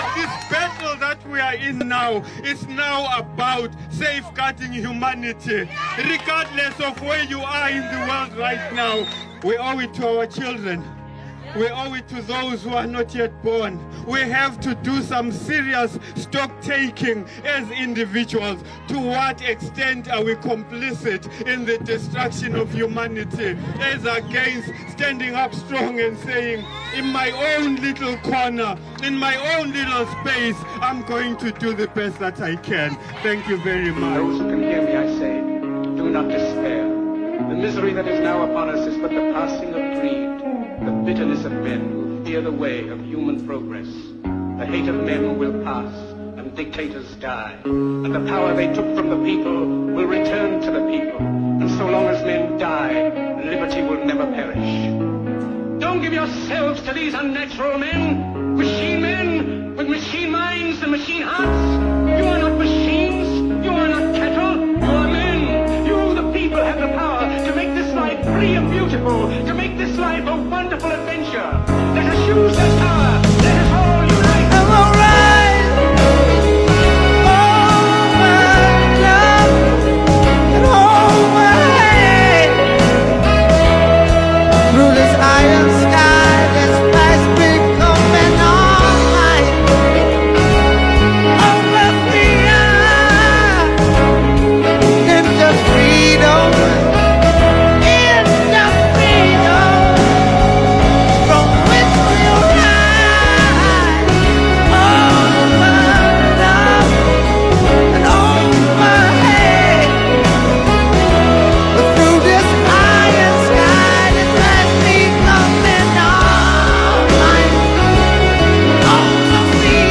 Du bist nicht allein! Zusammenstellung der weltweiten Demonstrationen für die Freiheit